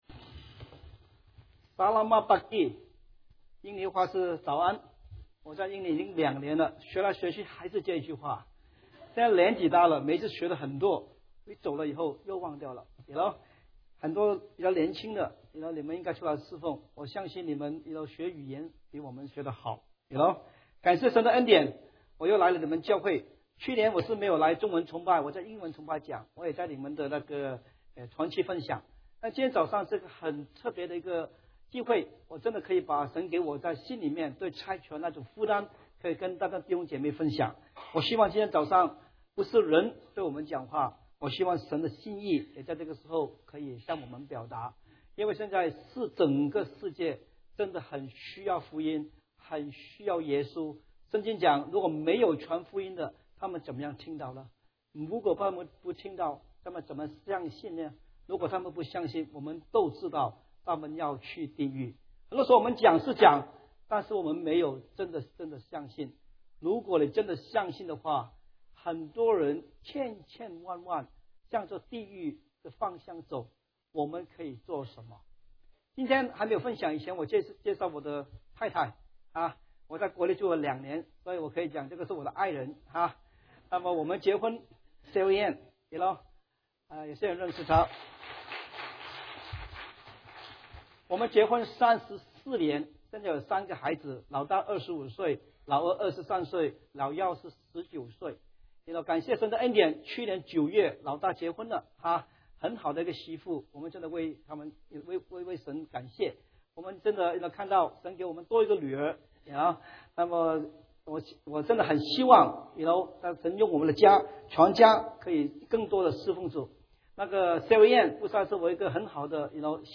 English Sermons 英語講道 | 基督教華府中國教會